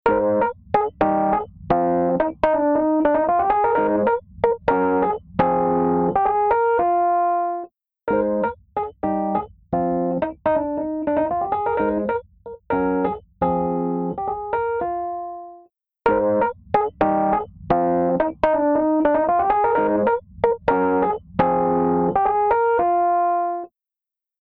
パワフルなディストーション＆サチュレーション・エンジン
CrushStation | Rhodes | Preset: The Smoothest, Crispiest Rhodes
CrushStation-Eventide-Rhodes-The-Smoothest-Crispiest-Rhodes.mp3